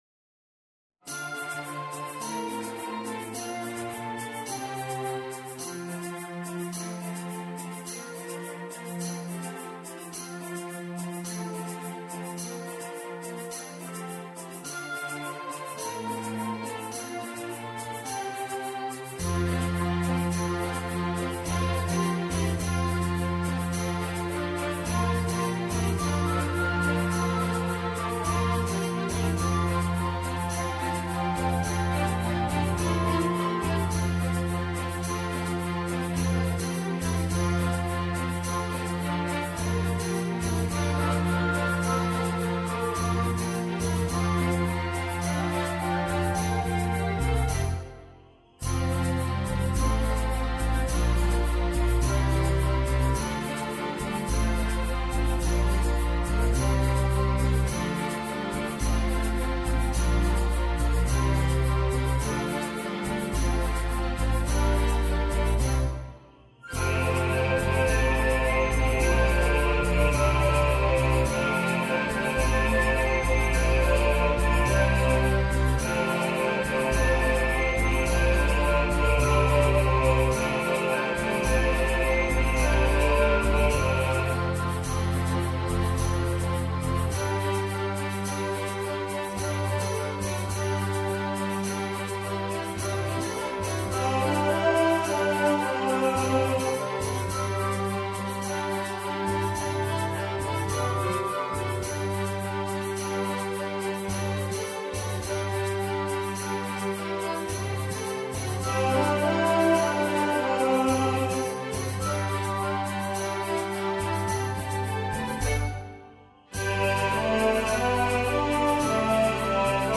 Road Goes On (Bass) | Ipswich Hospital Community Choir
Road-Goes-On-Bass.mp3